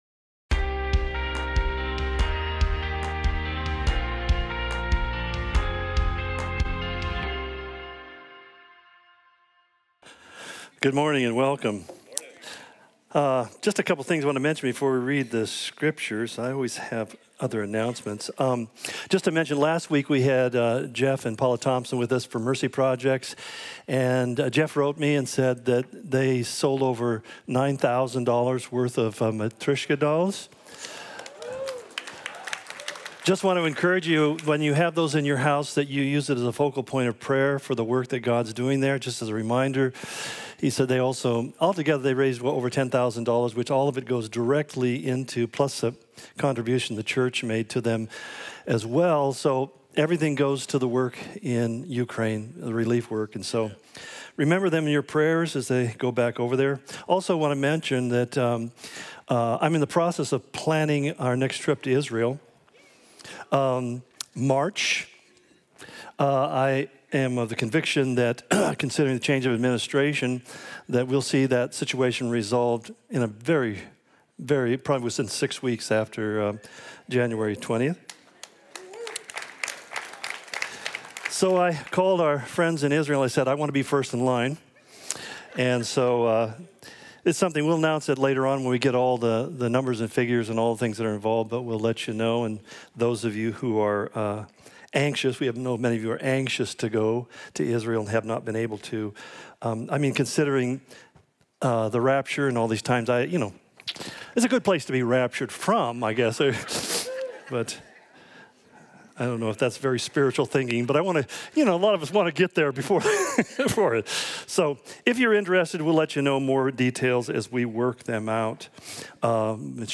Calvary Spokane Sermon Of The Week podcast